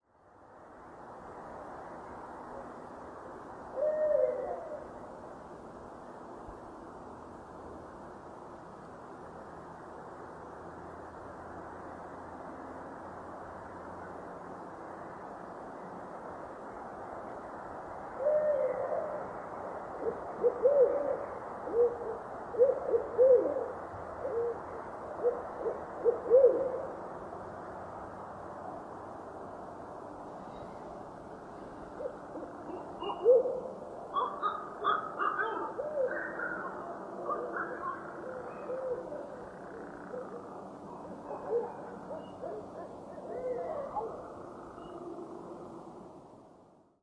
These are all night recordings, which should become obvious, and all recorded right from the backyard.
All clips had a little noise reduction done to cut down the normal background hiss, and one was amplified a little, but for all, I’d recommend headphones.
Those, all of them (well, except for the frog croaking and background traffic,) are barred owls (Strix varia) – those are the sounds they make as mated pairs.
I had to amplify this recording a little, but likely not because of great distance – barred owls have a faint and echo-ey call that makes them sound twice as far away as they actually are, so if you hear them clearly, they’re generally within 50 meters.